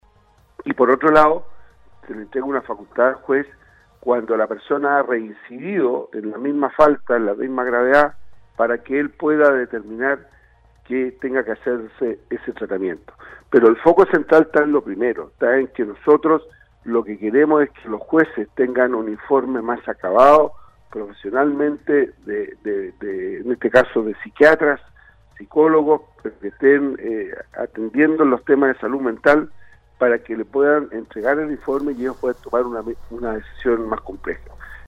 Prohens sostuvo en contacto telefónico con Nostálgica donde explicó que dentro del proyecto se contempla contar con un peritaje siquiátrico para imputados por este tipo de delitos que opten por una libertad vigilada, “La castración es una alternativa a un proceso anterior. Nosotros lo que hemos dicho es que hoy día los jueces cuando toman la decisión de acoger a un condenado se basan en un informe psicosocial que no es suficiente para que se tome una determinación en casos de violación”, argumentó.